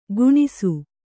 ♪ guṇisu